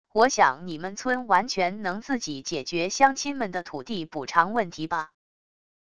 我想你们村完全能自己解决乡亲们的土地补偿问题吧wav音频生成系统WAV Audio Player